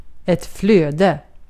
Ääntäminen
Synonyymit ström flod älv Ääntäminen Tuntematon aksentti: IPA: /ˈflø̀ːde/ Haettu sana löytyi näillä lähdekielillä: ruotsi Käännös 1. virtaus Artikkeli: ett .